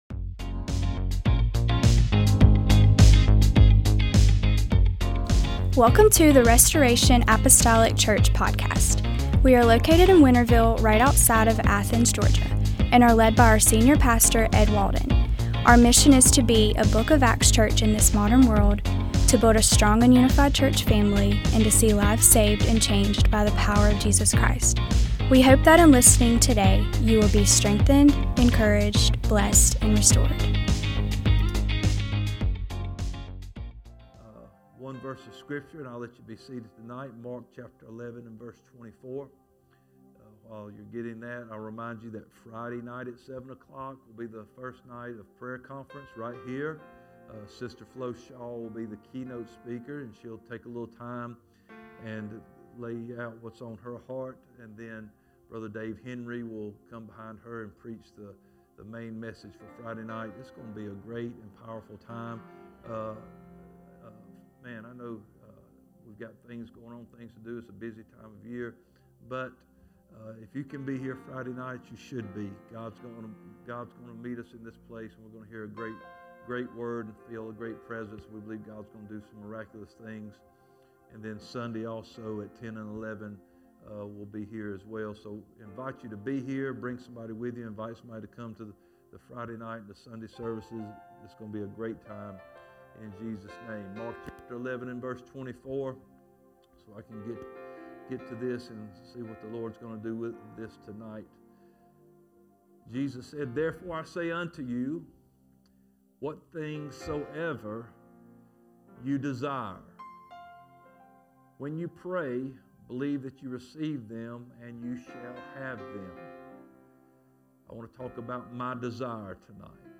MDWK Service